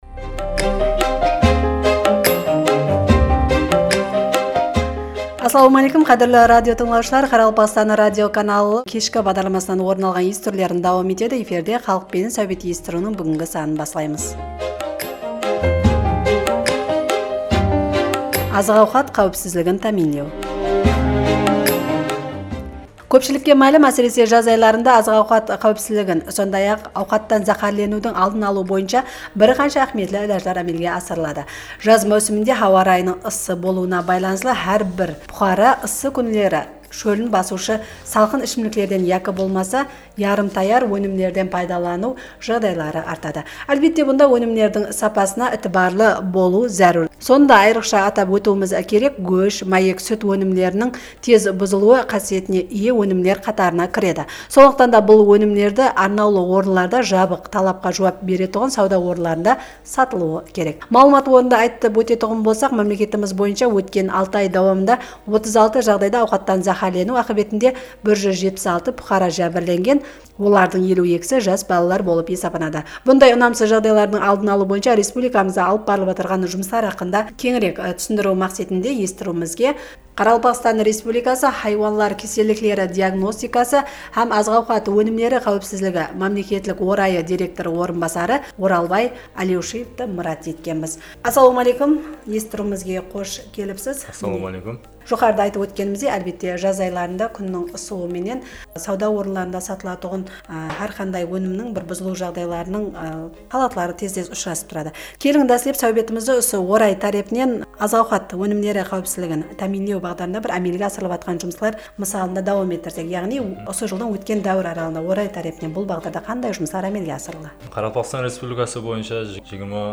Búgin Qaraqalpaqstan Respublikası Ministrler Keńesiniń májilisler zalında sharwa-fermer xojalıqları basshıları menen ushrasıw bolıp ótti.